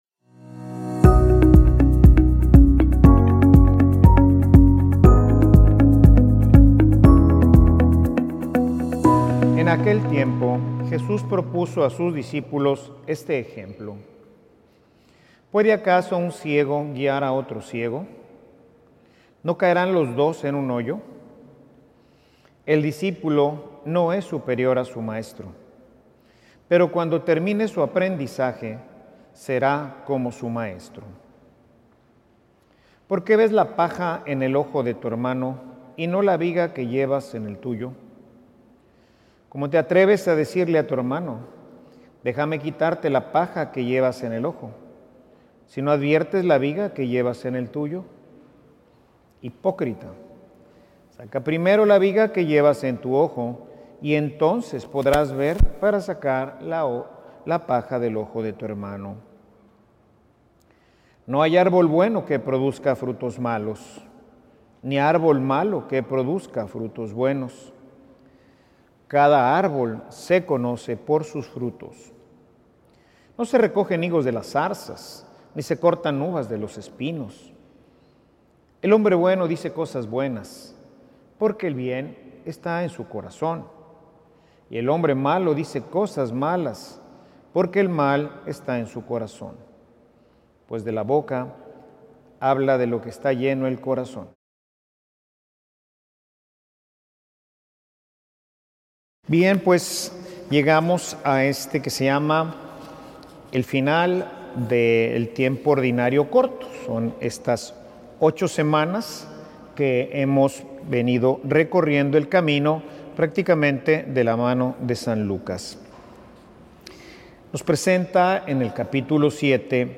Escucha este audio aquí 0:00 0:00 0.5x 0.75x Normal 1.25x 1.5x Mira el video de esta homilía Ver en YouTube Hermanos, en algún momento de nuestras vidas todos hemos sido ciegos espirituales.
homilia_Que_tan_parecido_eres_a_tu_maestro.mp3